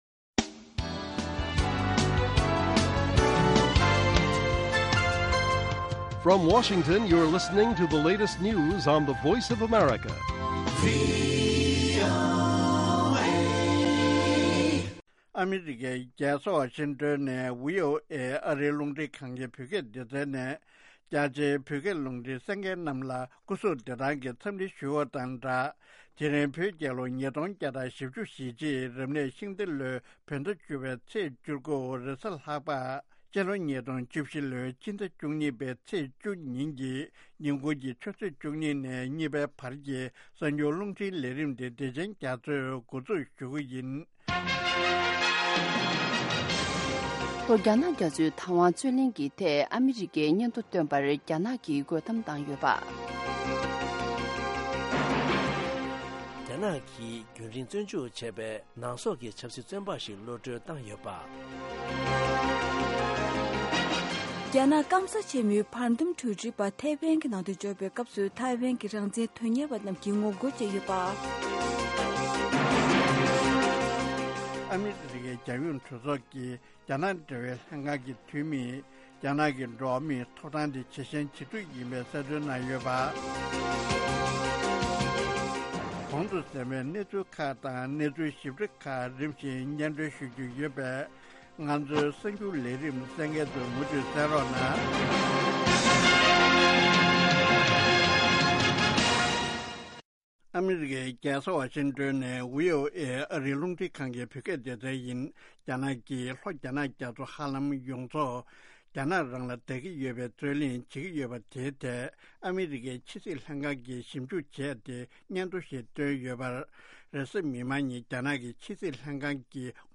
ཉིན་ལྟར་ཐོན་བཞིན་པའི་བོད་དང་ཨ་རིའི་གསར་འགྱུར་ཁག་དང་། འཛམ་གླིང་གསར་འགྱུར་ཁག་རྒྱང་སྲིང་ཞུས་པ་ཕུད།